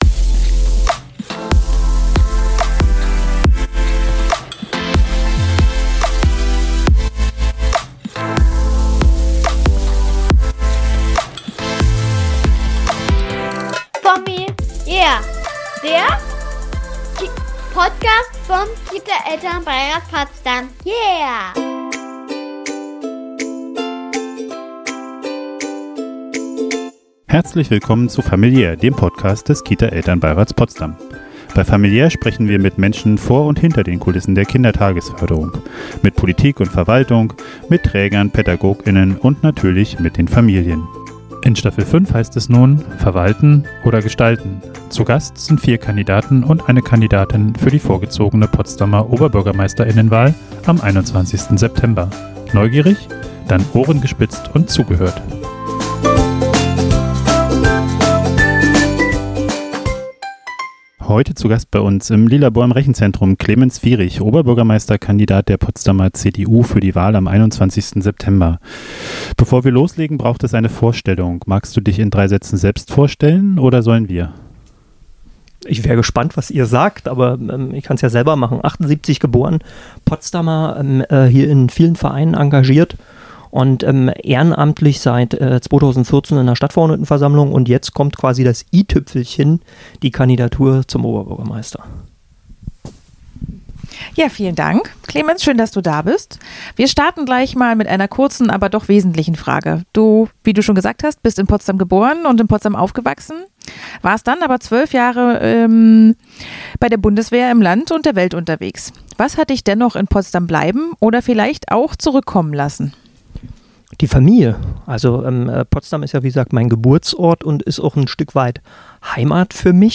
Zur fünften Staffel begrüßen wir vier Kandidaten und eine Kandidatin zur vorgezogenen Potsdamer Oberbürgermeister*innenwahl im Lilabor im Rechenzentrum.